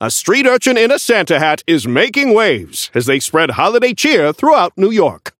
Newscaster voice line - A street urchin in a Santa hat is making waves, as they spread holiday cheer throughout New York!
Newscaster_seasonal_synth_unlock_01_alt_01.mp3